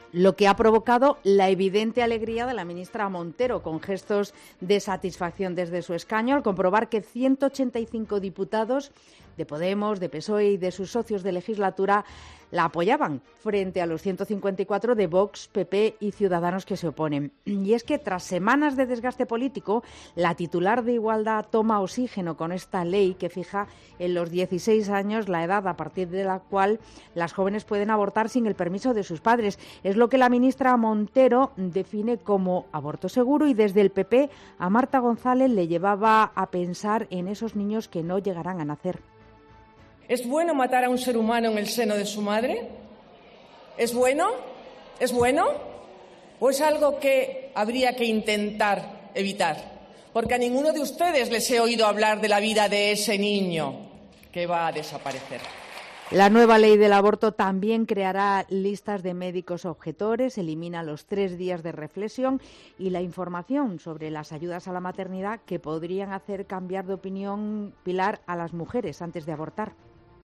Te da más detalles la redactora de COPE